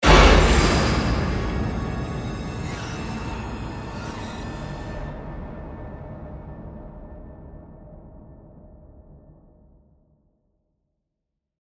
• Fuzzy Jumpscare
Confused excited crowd slight panic and distress
horror, jumpscare
FuzzyJumpscare.mp3